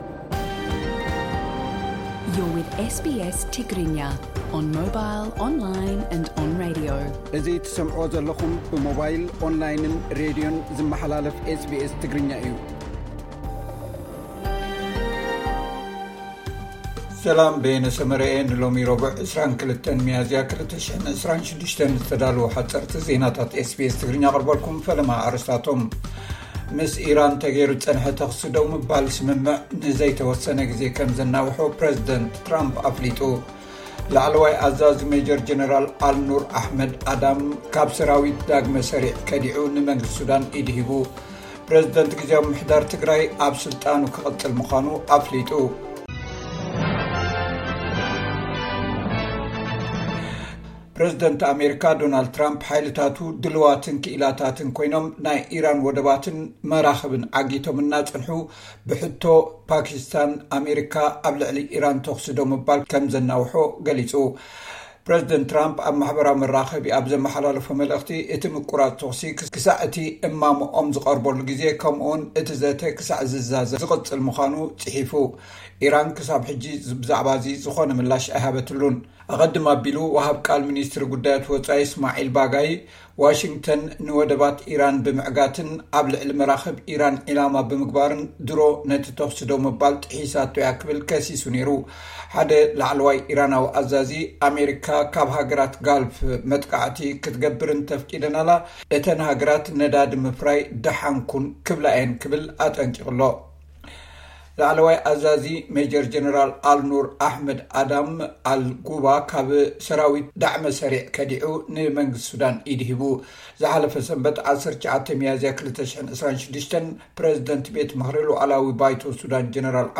SBS Tigrinya Newsflash